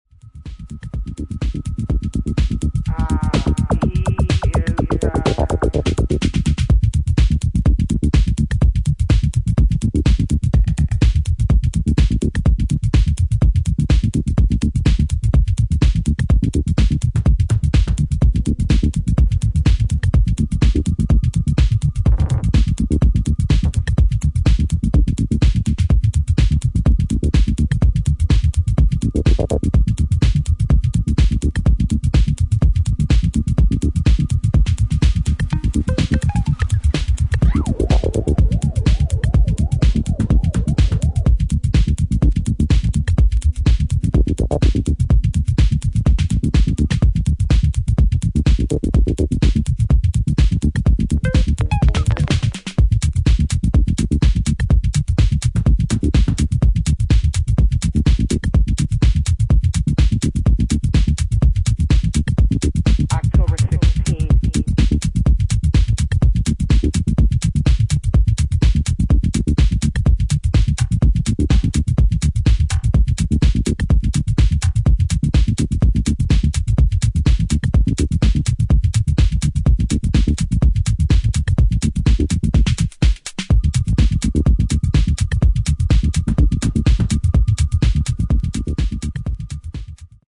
303風味のアシッドなベースサウンドをレイヤー的に用い、ドライブ感のある現代的なミニマルハウスへと昇華しています。